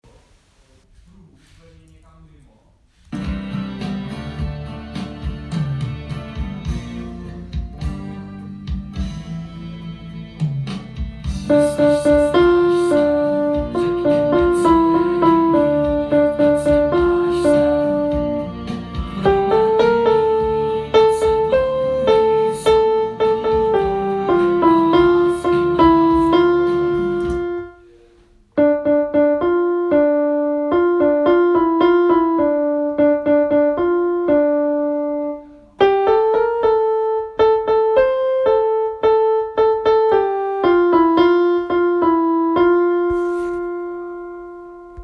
Malovani – hudba klavir pak pouze klavir